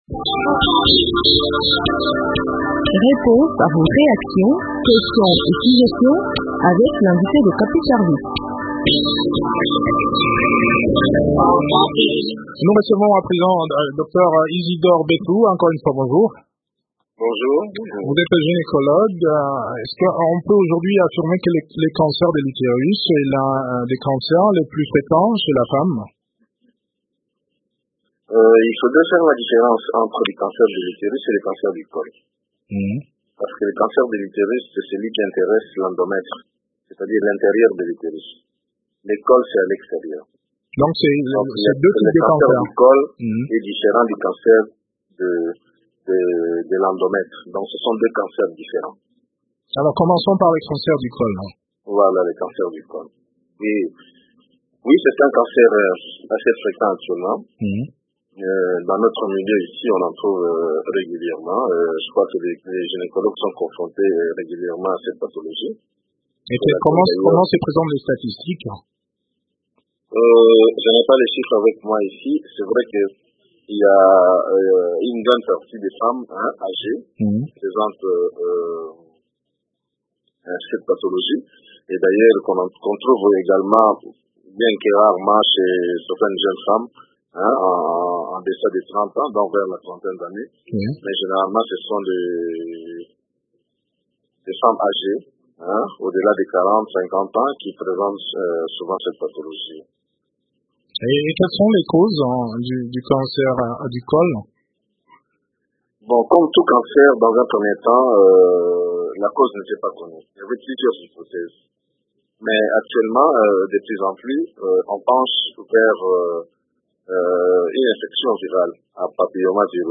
expert en gynécologie obstétrique